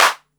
TR 808 Clap 01.wav